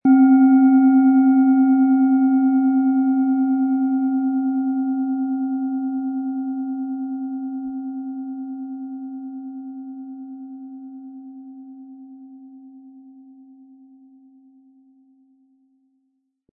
Planetenton 1
Die Planetenklangschale DNA ist handgefertigt aus Bronze.
Im Sound-Player - Jetzt reinhören können Sie den Original-Ton genau dieser Schale anhören.
Diese Schale hat eine kleine Einkerbung am Rand, was den Klang nicht beeinträchtigt und somit kein Mängel ist.
Spielen Sie die Schale mit dem kostenfrei beigelegten Klöppel sanft an und sie wird wohltuend erklingen.